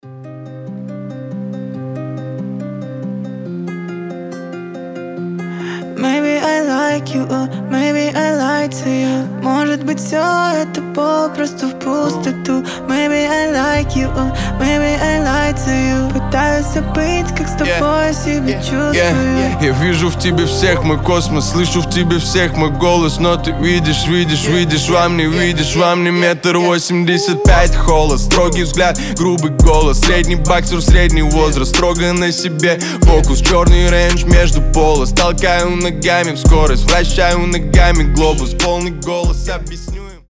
рэп
дуэт